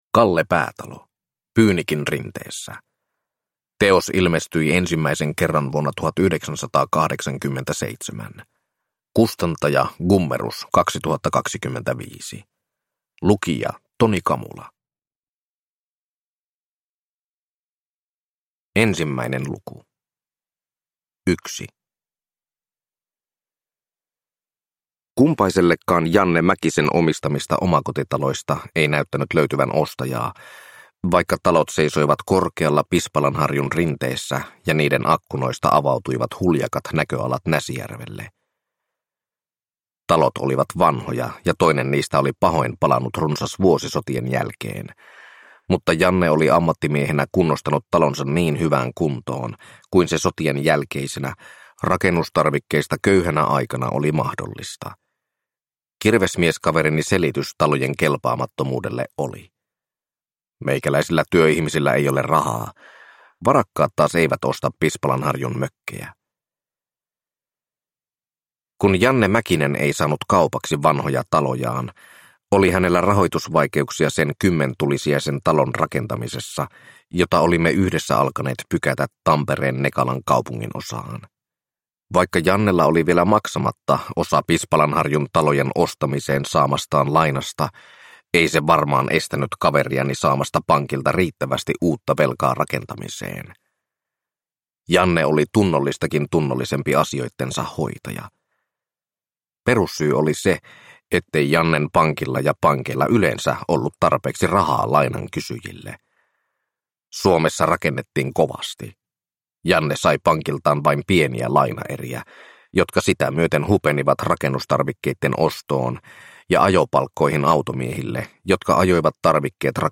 Pyynikin rinteessä (ljudbok) av Kalle Päätalo